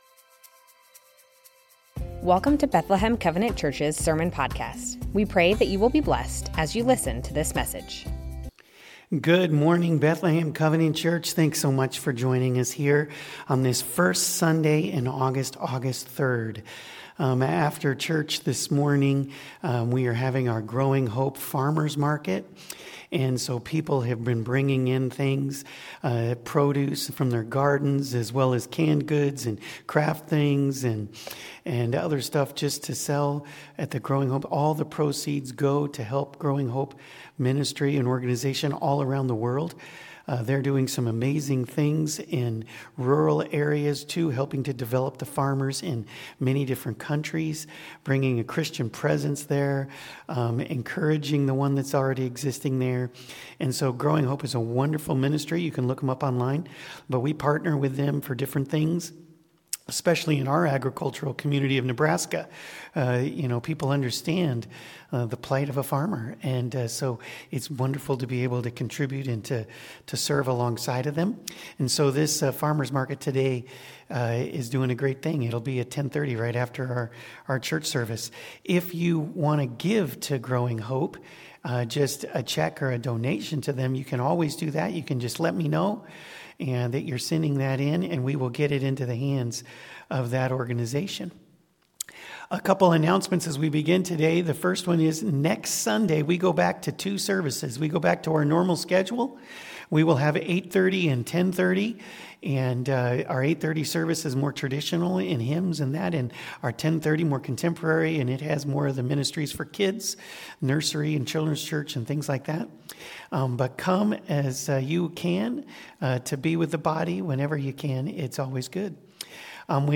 Bethlehem Covenant Church Sermons Matthew 25:31-46 - The Sheep and the Goats Aug 03 2025 | 00:33:48 1x 00:00 / 00:33:48 Subscribe Share Spotify RSS Feed Share Link Embed